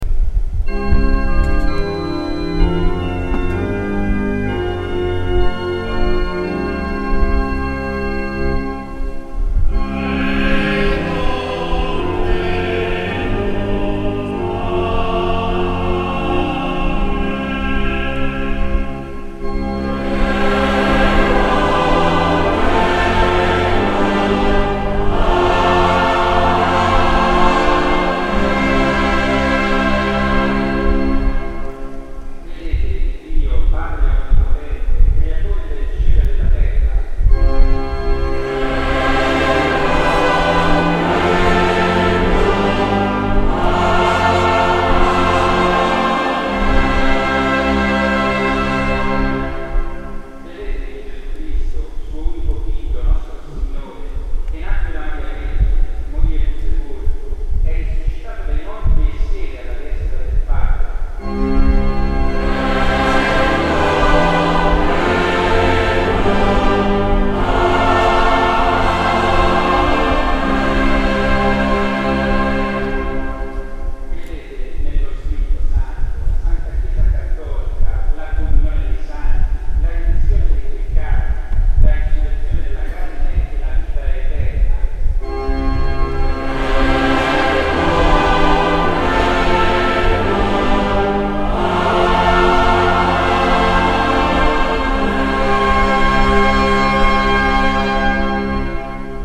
Gallery >> Audio >> Audio2022 >> Ordinazione Arcivescovo Isacchi >> 12-CredoAmen OrdinazArcivescovo 31Lug22